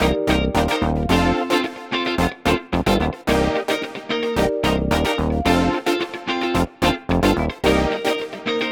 30 Backing PT1.wav